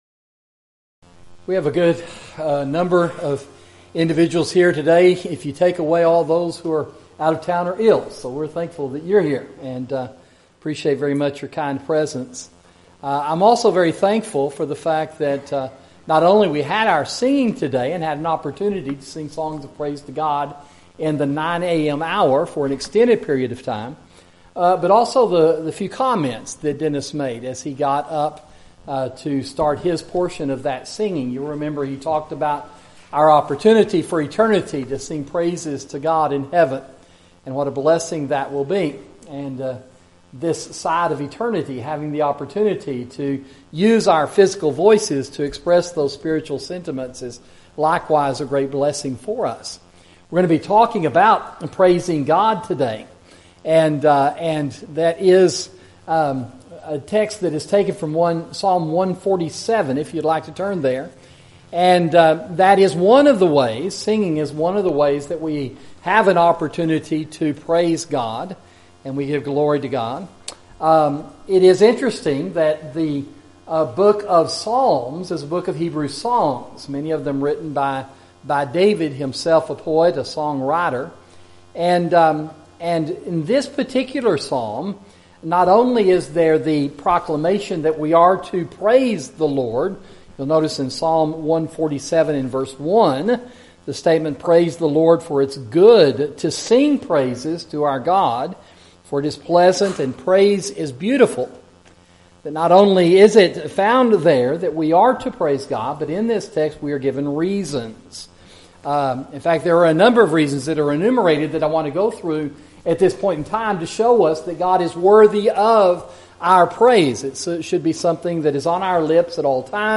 Sermon: Praise Your God!